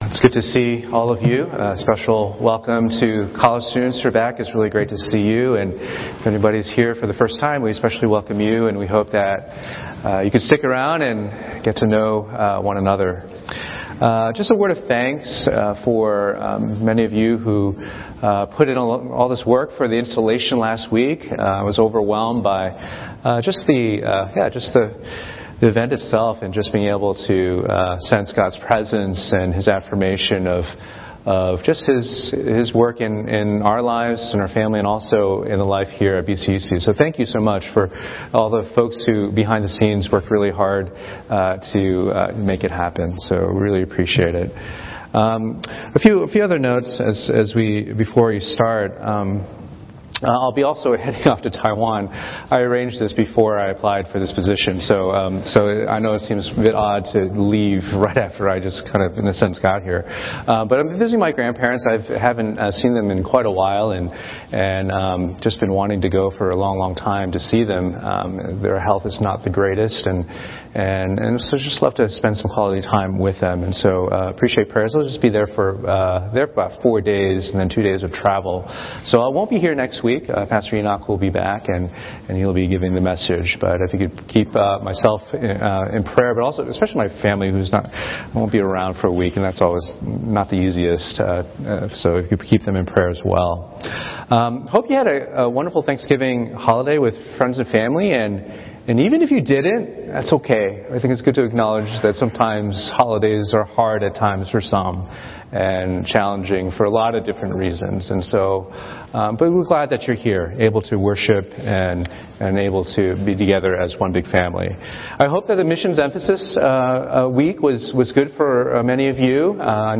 Sermons - Page 29 of 74 | Boston Chinese Evangelical Church